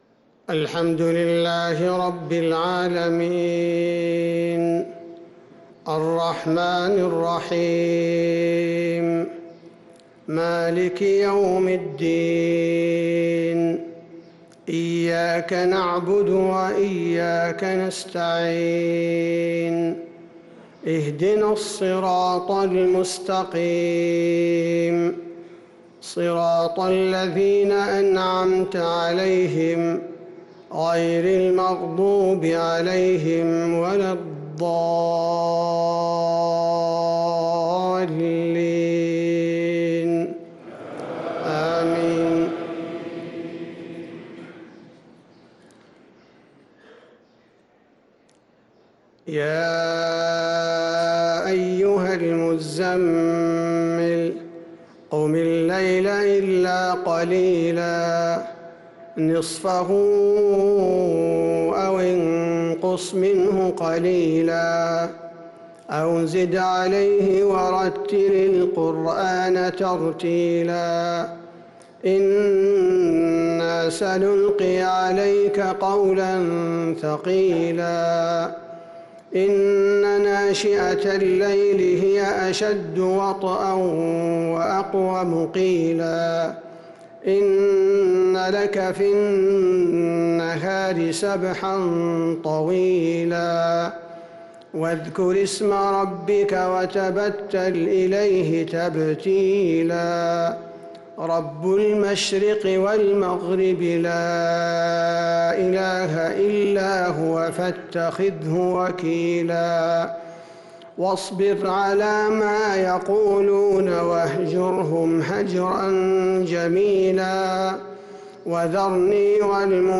فجر الثلاثاء 4-9-1446هـ سورة المزمل كاملة | Fajr prayer Surat al-Muzammil 4-3-2025 > 1446 🕌 > الفروض - تلاوات الحرمين